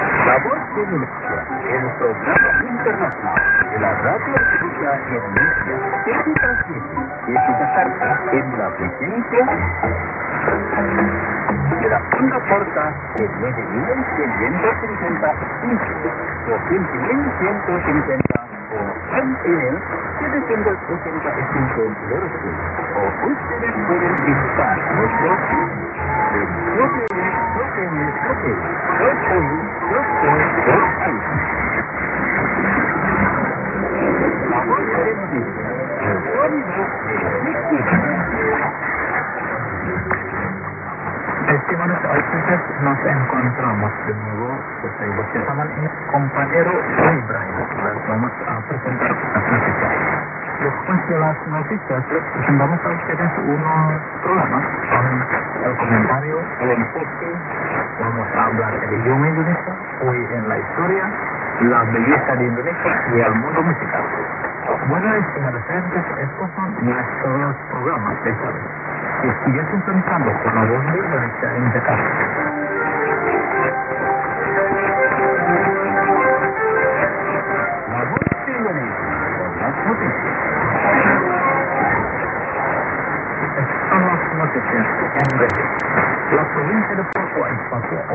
・このＨＰに載ってい音声(ＩＳとＩＤ等)は、当家(POST No. 488-xxxx)愛知県尾張旭市で受信した物です。
ID: identification announcement